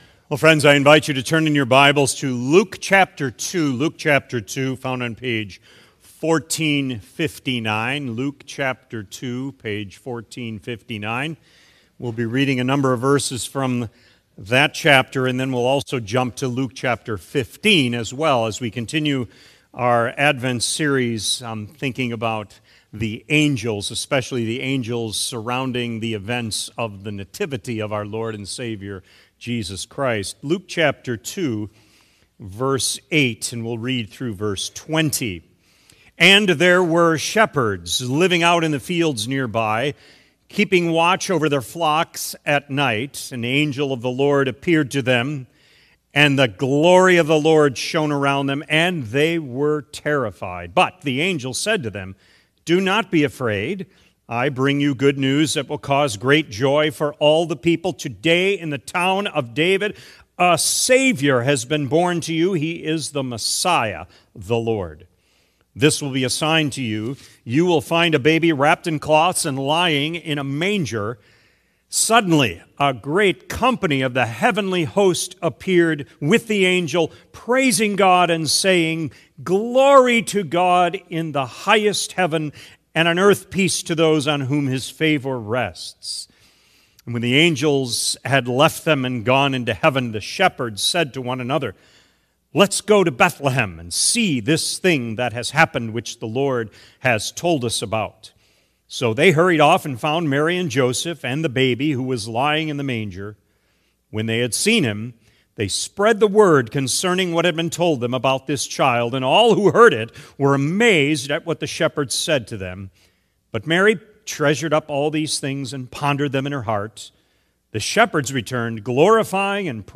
Sermon Recordings | Faith Community Christian Reformed Church
“Those Celebrating Angels” December 21 2025 A.M. Service